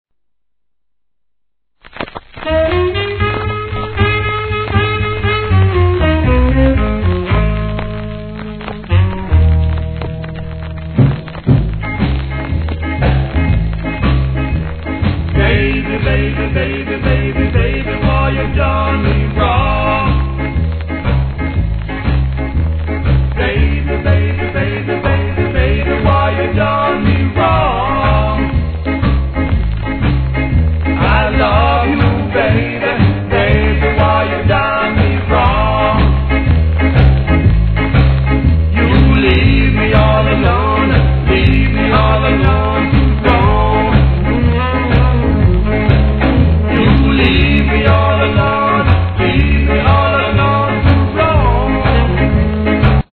C 序盤にチリ入りますが落ち着きます
1. REGGAE